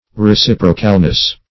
Search Result for " reciprocalness" : The Collaborative International Dictionary of English v.0.48: Reciprocalness \Re*cip"ro*cal*ness\ (r[-e]*s[i^]p"r[-o]*kal*n[e^]s), n. The quality or condition of being reciprocal; mutual return; alternateness.
reciprocalness.mp3